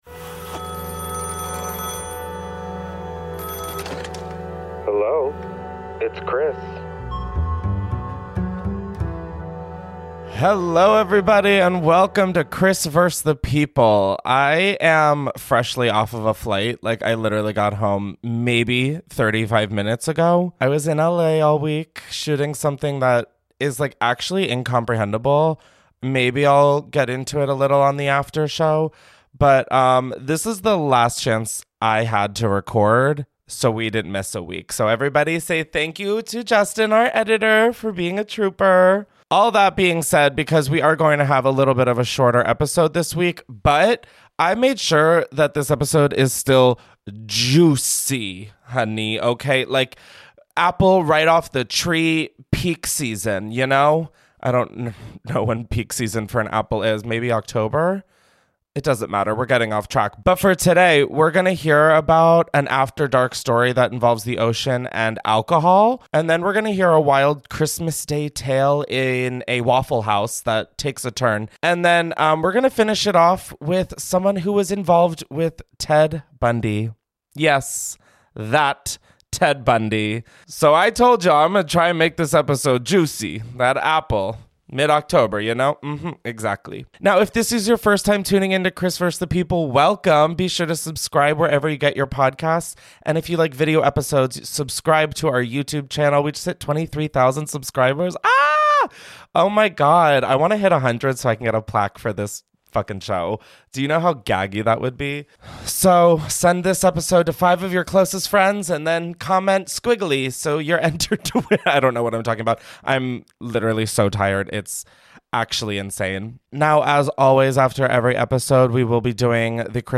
First, a caller takes us to Portugal for what was supposed to be a dreamy couples’ kayaking trip… until €1 shots, a rocky ocean, and a seasick boyfriend turn the excursion into a full-blown puke parade.